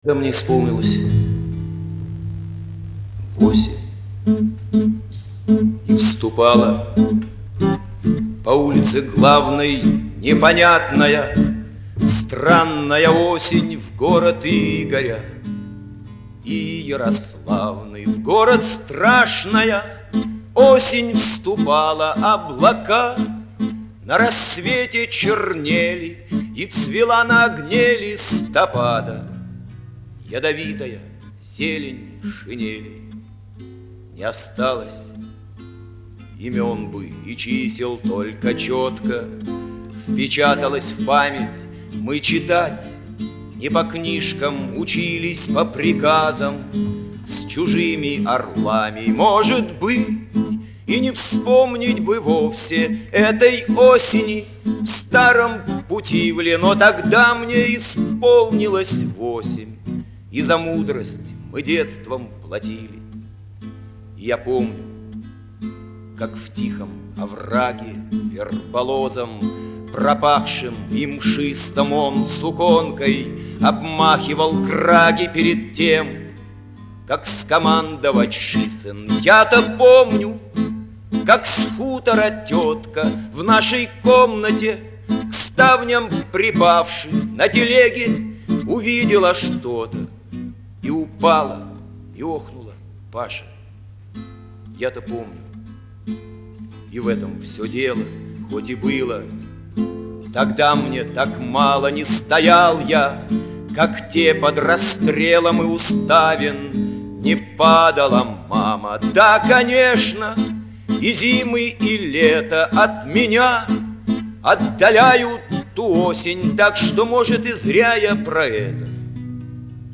Почти «наша» мелодия (авторское исполнение):